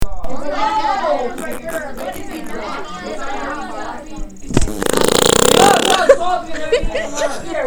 Play Fart D - SoundBoardGuy
Play, download and share fart D original sound button!!!!
fart-d.mp3